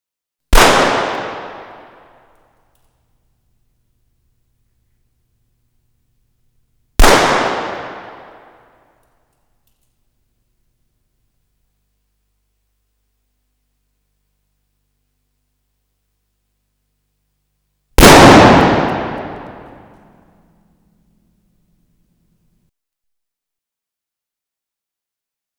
08.枪声声效.wav